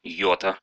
Ääntäminen
US : IPA : [aɪ.ˈoʊ.ɾə] UK : IPA : /aɪ.ˈəʊ.tə/ US : IPA : /aɪ.ˈoʊ.tə/ IPA : /ajˈowɾə/